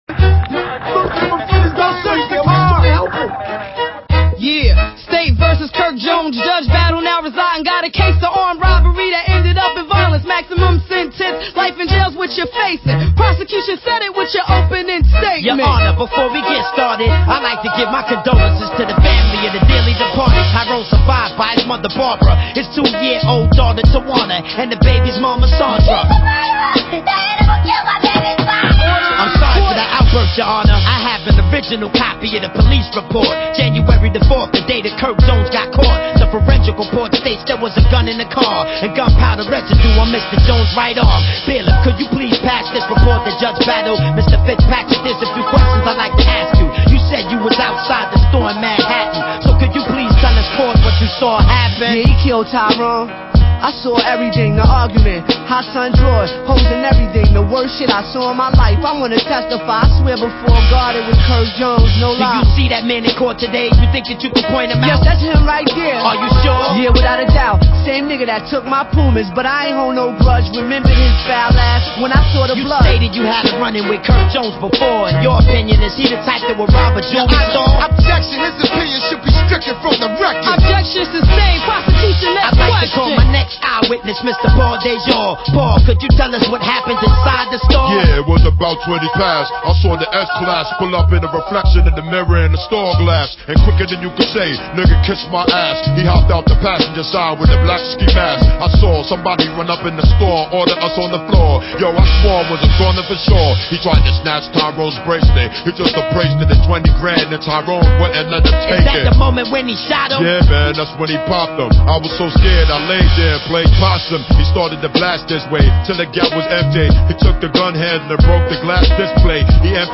Назад в (rap)...